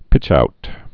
(pĭchout)